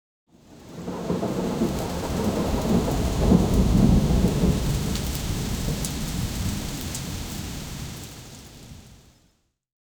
60 second soundscape of rain sounds coming from the inside of a train, thunder off in the distance and rain
60-second-soundscape-of-r-u6f4jbcd.wav